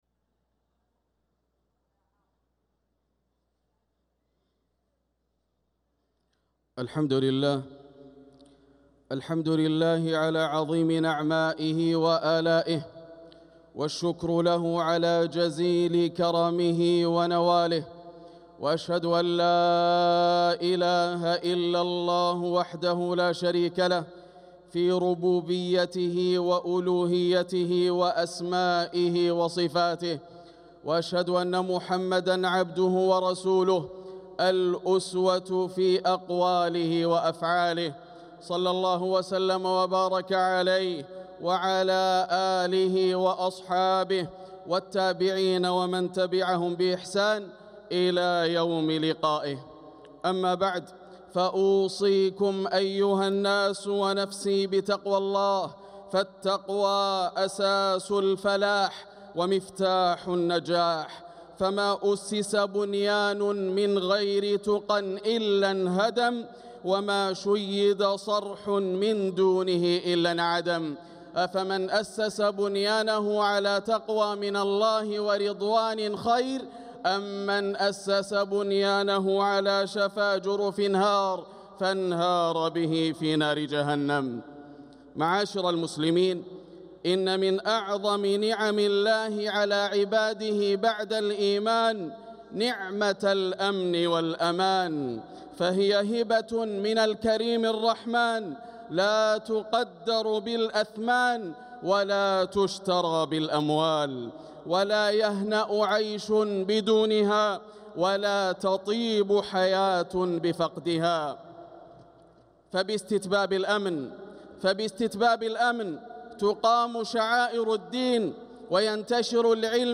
خطبة الجمعة 8-4-1446هـ بعنوان نعمة الأمن والأمان > خطب الشيخ ياسر الدوسري من الحرم المكي > المزيد - تلاوات ياسر الدوسري